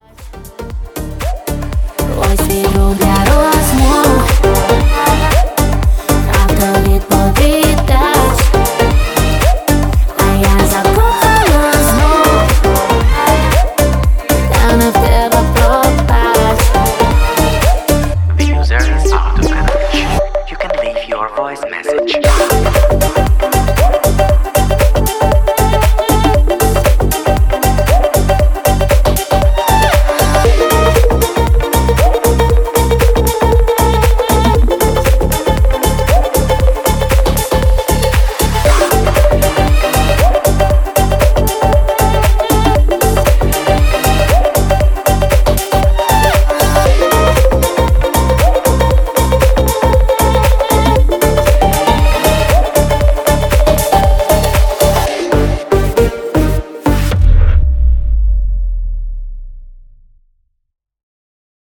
• Качество: 192, Stereo
поп
ритмичные
deep house
восточные мотивы
красивый женский голос